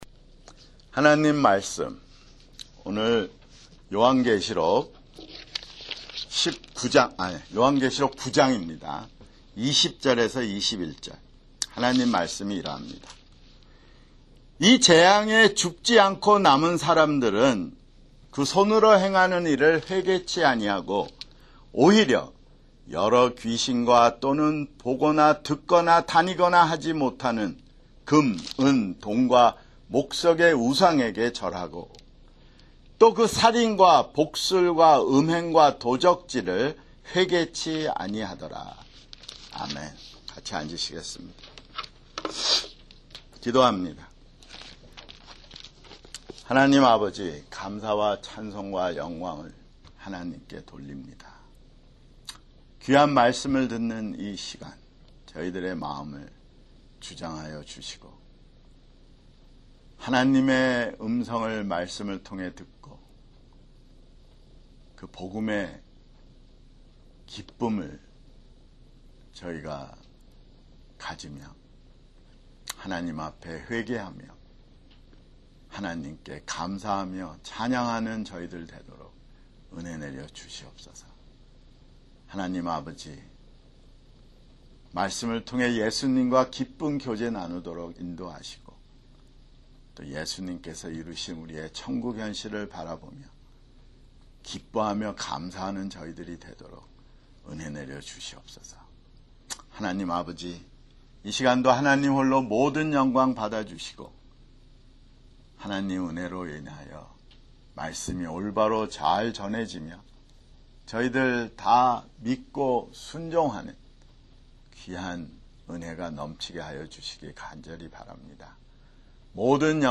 [주일설교] 요한계시록 9장 20-21절